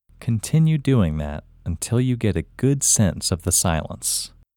LOCATE OUT English Male 32
Locate-OUT-Male-32.mp3